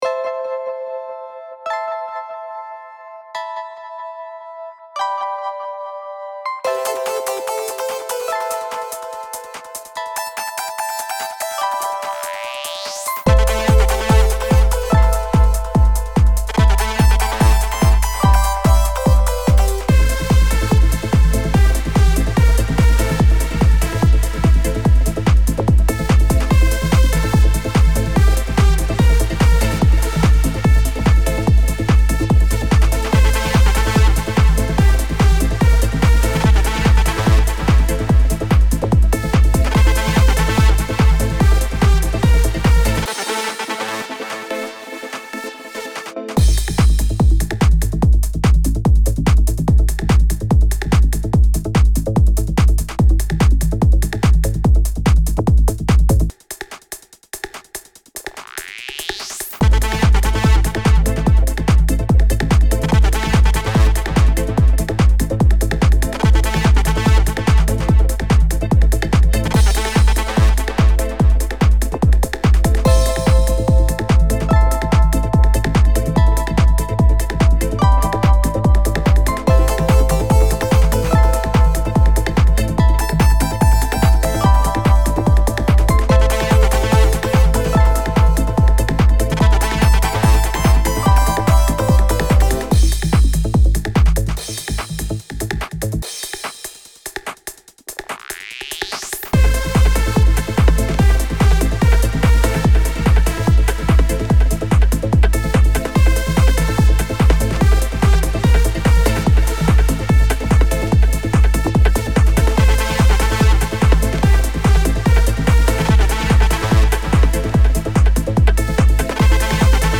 Industrial Wave